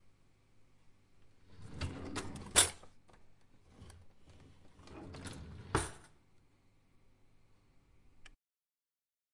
抽屉打开 关闭
描述：木制抽屉打开和关闭。里面的餐具随着它的移动而屎。
Tag: 厨房 餐具 - 内部 打开 关闭 抽屉 OWI 关机